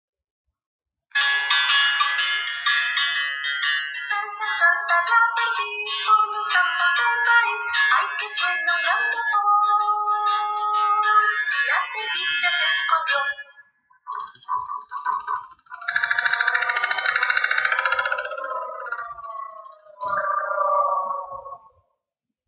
AUDIOCUENTO BASADO EN LA CANCION DE JUDITH AKOSCHKY